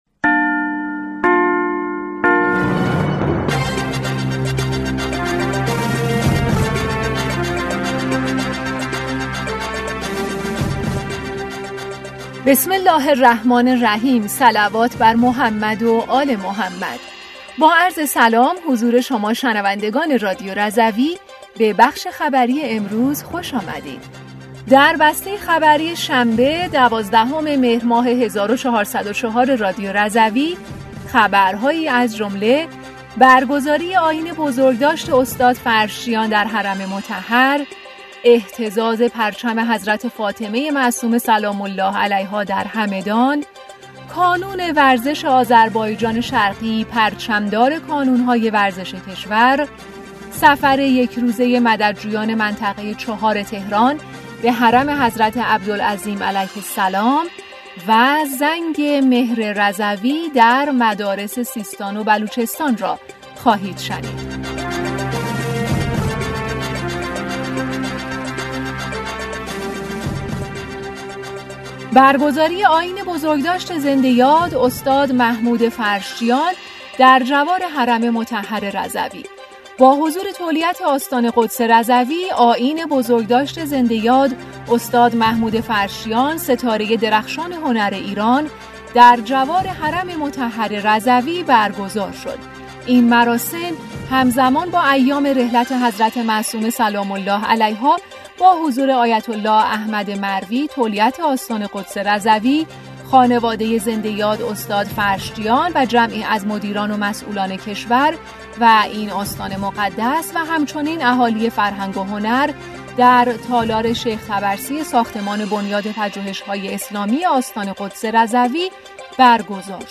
بسته خبری ۱۲ مهر ۱۴۰۴ رادیو رضوی؛